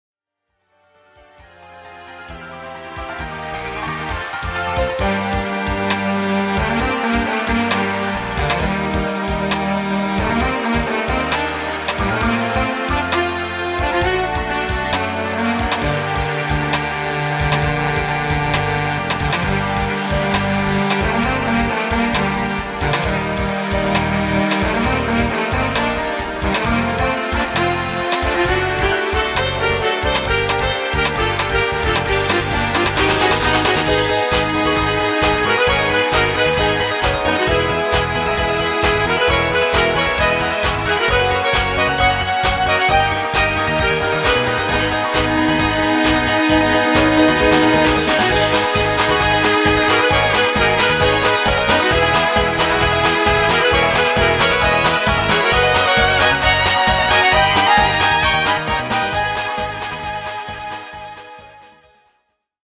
梅雨…というよりも、雨が降っている感じを曲で再現してみました。